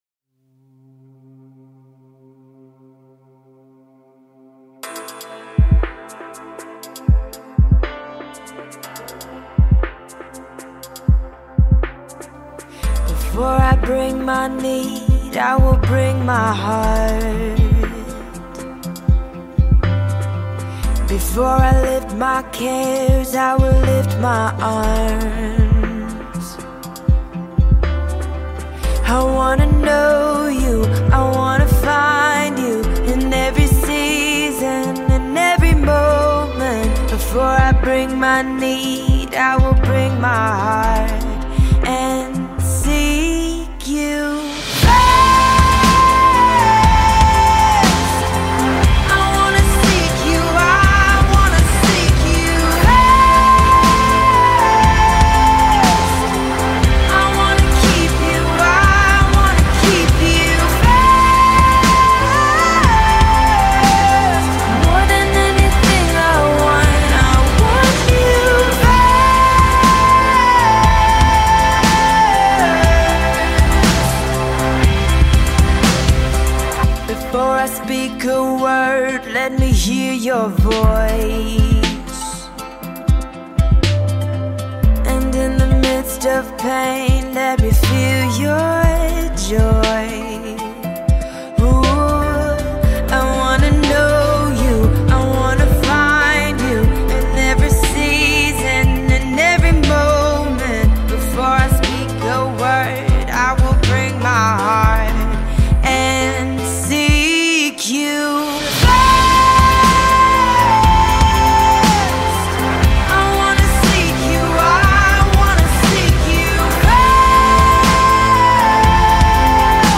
Genre Contemporary Christian music, soul, worship